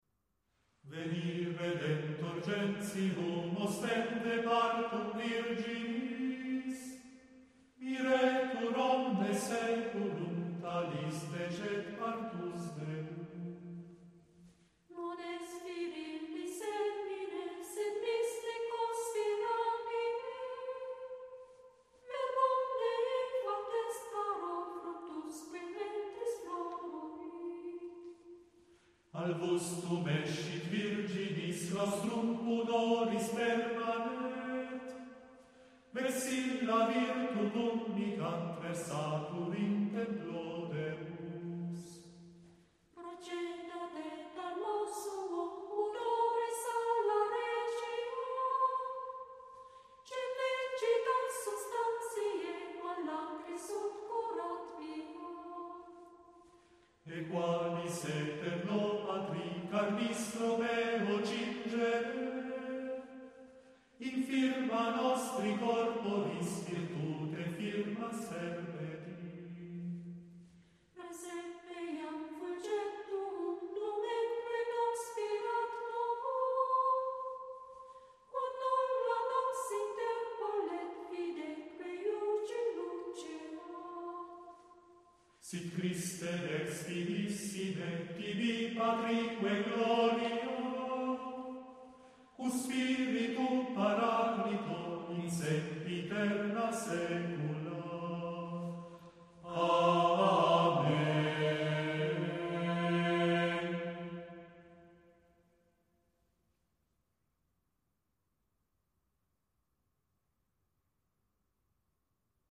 versione ritmica